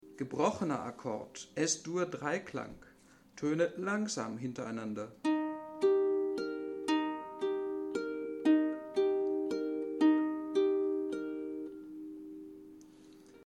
Ein gebrochener Akkord entsteht, wenn die Töne des Akkordes in immer gleicher Reihenfolge wiederholend hintereinander einzeln gespielt werden.
Gebrochener Akkord, aufwärts